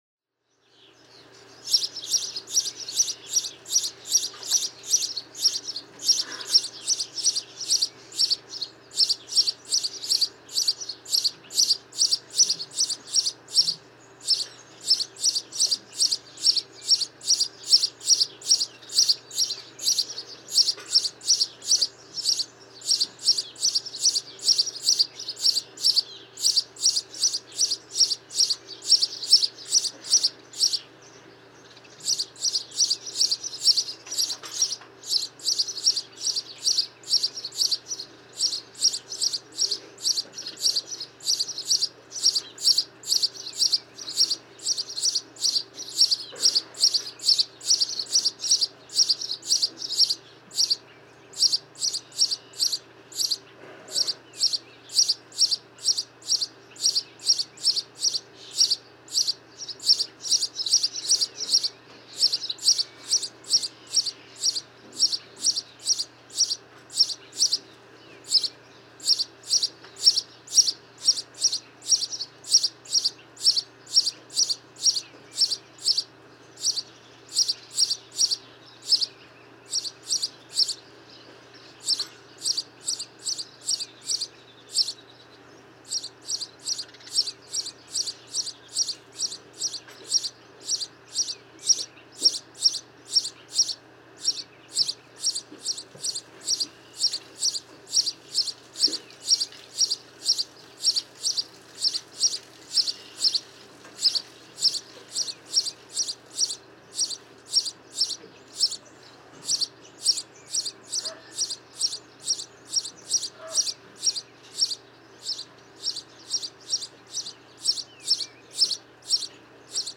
House sparrow
♫363. Chirrup, chirrup, chirrup . . . on the farm, with cattle nearby—a rather tame singing performance, it seems, energetic but with so little variety.
363_House_Sparrow.mp3